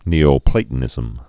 (nēō-plātn-ĭzəm)